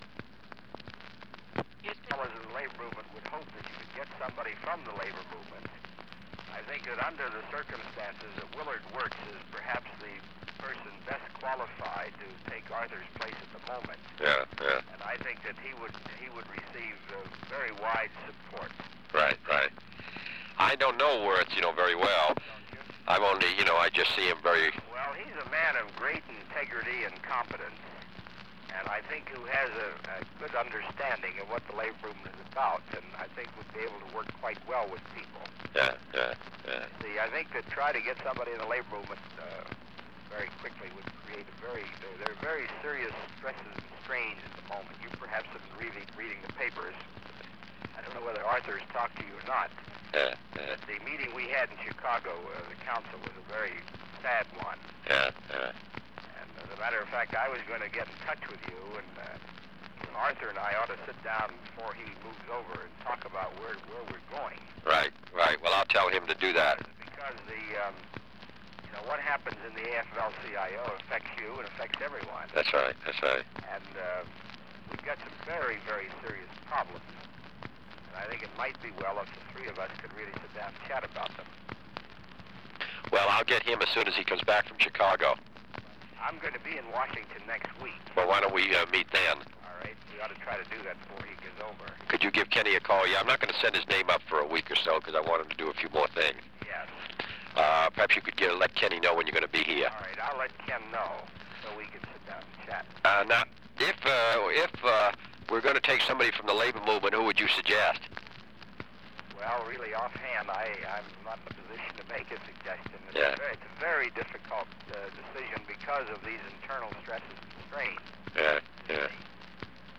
Conversation with Walter Reuther
Secret White House Tapes | John F. Kennedy Presidency Conversation with Walter Reuther Rewind 10 seconds Play/Pause Fast-forward 10 seconds 0:00 Download audio Previous Meetings: Tape 121/A57.